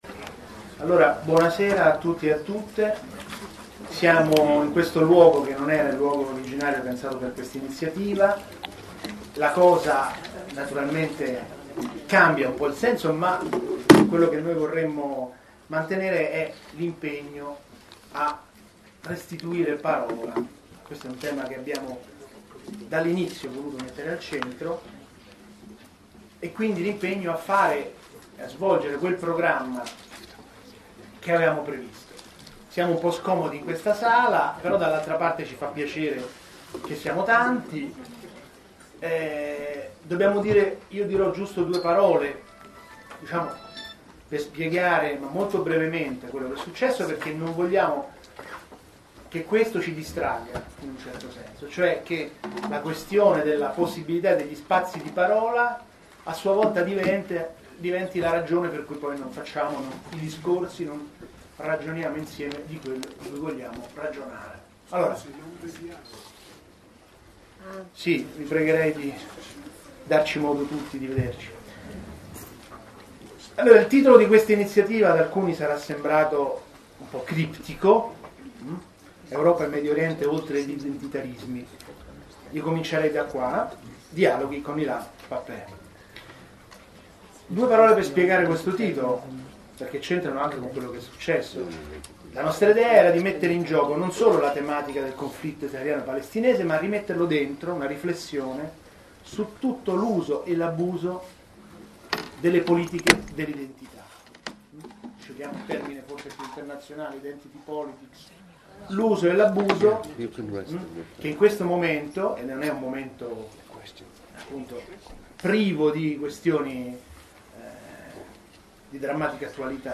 Moni Ovadia, attore e scrittore
Sala Accademia, Centro congressi Frentani- Roma - 16 febbraio 2015 ore 14.00